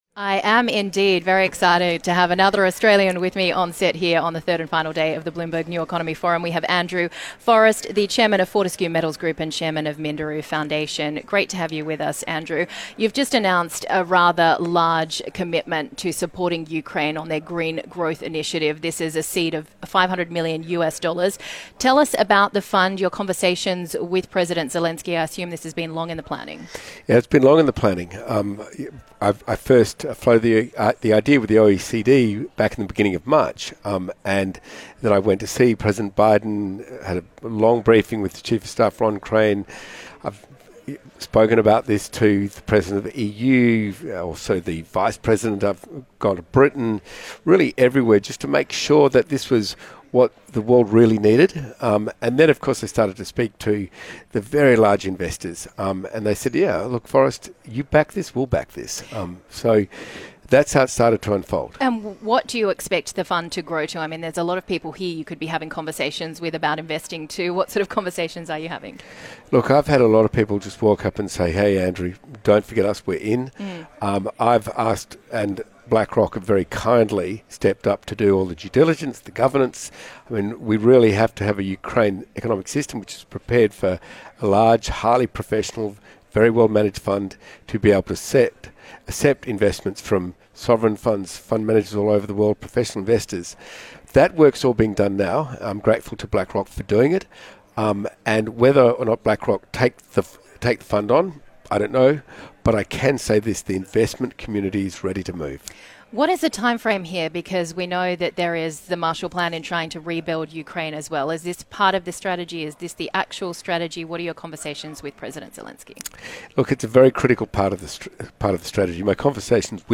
Andrew Forrest, Founder and Chairman of Fortescue Metals Group, talks on the green economy.